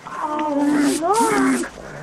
• GRUNTING PERSONS.wav
GRUNTING_PERSONS_Zij.wav